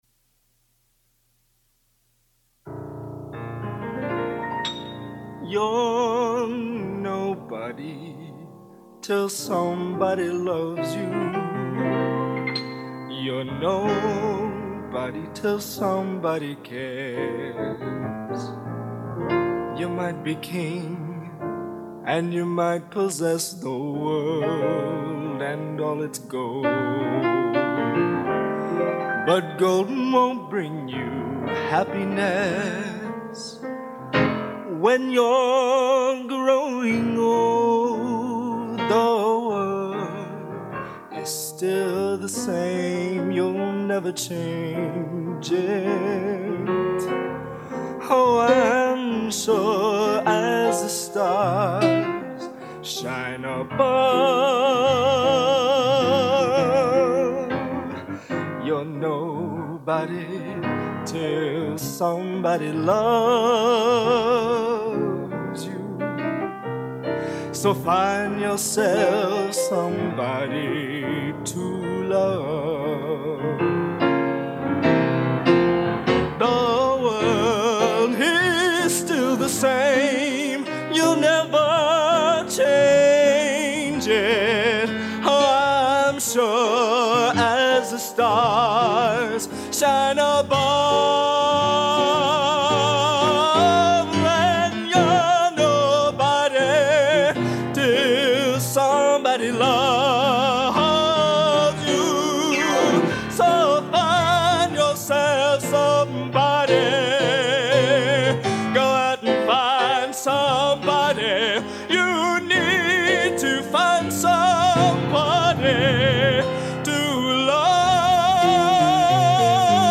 Genre: Popular / Standards Schmalz | Type: Studio Recording